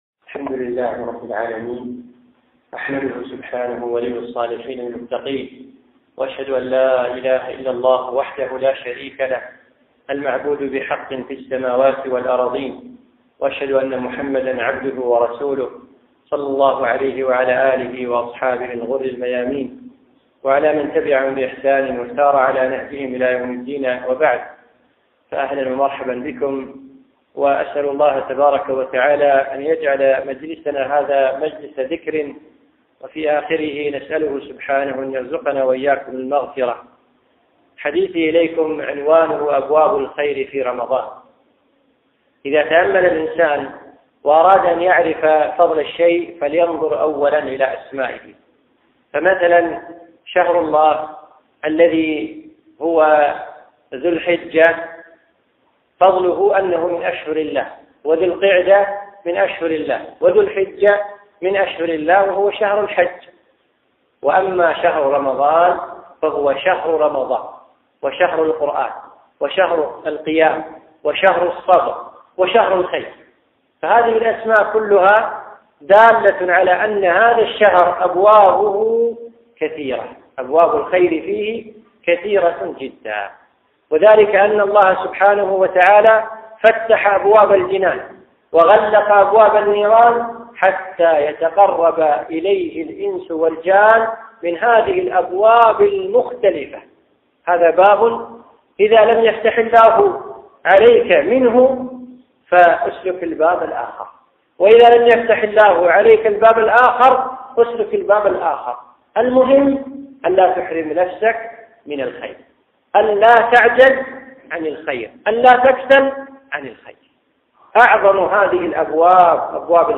محاضرة - أبواب الخير في رمضان